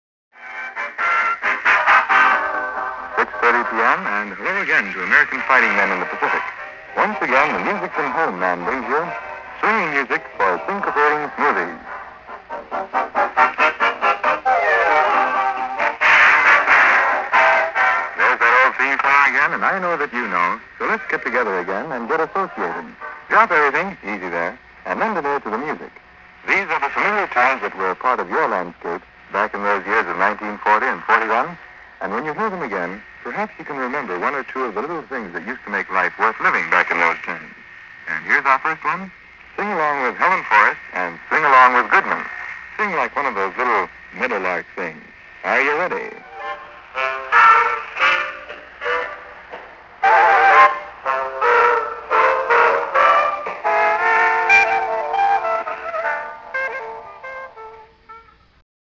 Listening to 'The Zero Hour' A segue from Tokyo Rose to "Syncopated Music".